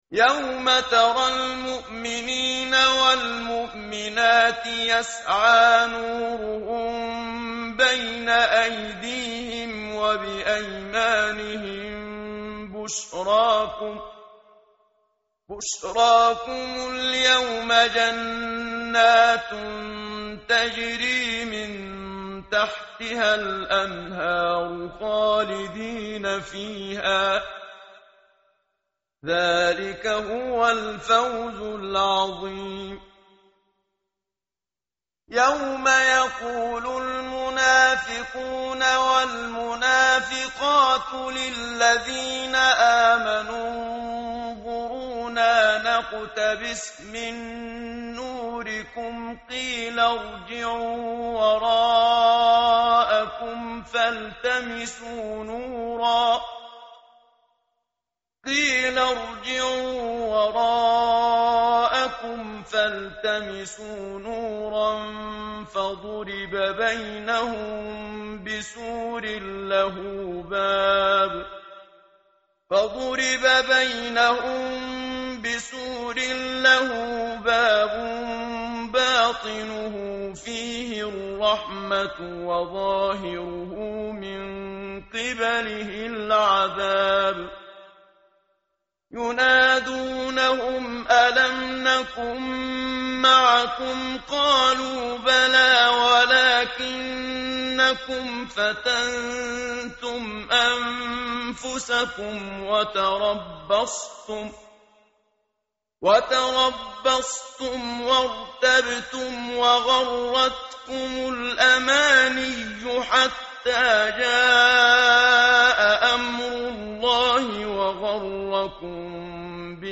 متن قرآن همراه باتلاوت قرآن و ترجمه
tartil_menshavi_page_539.mp3